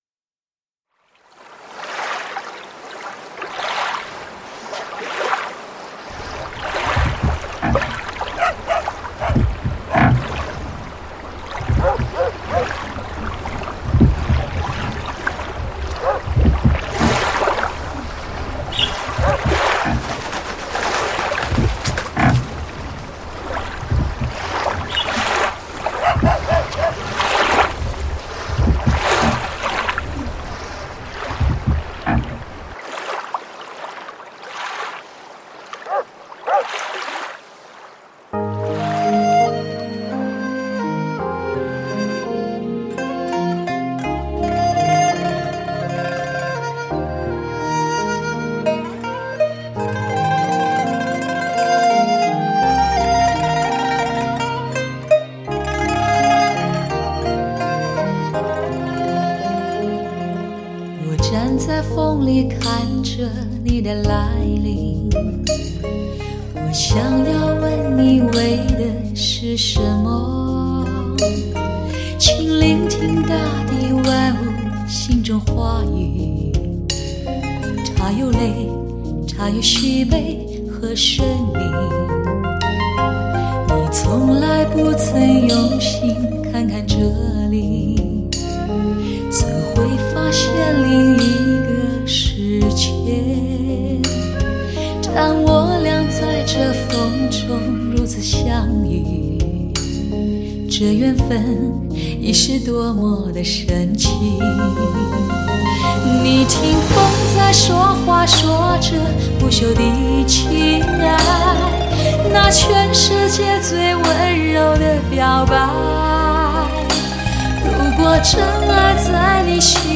优美的歌声犹如雄鹰的翅膀在空中缭绕盘旋，令人回想起那魂牵梦萦的故乡......